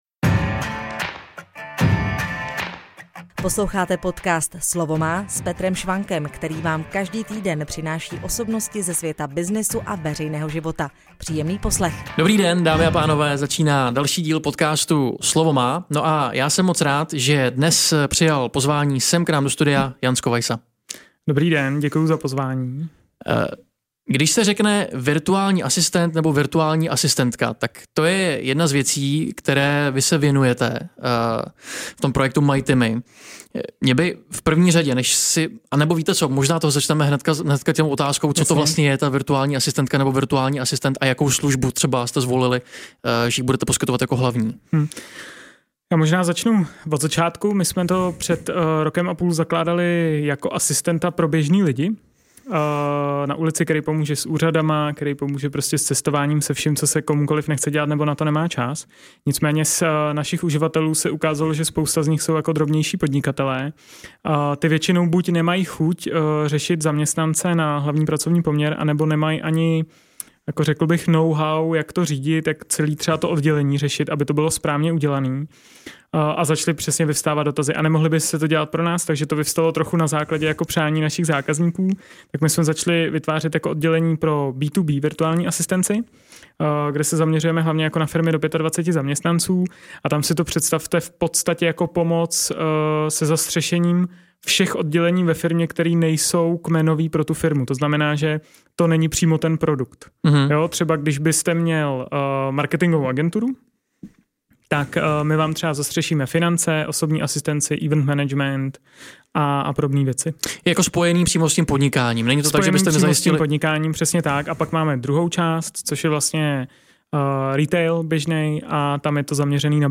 Jak se vůbec takový projekt dsává dohromady a jak funguje? Odpovědi naleznete v rozhovoru.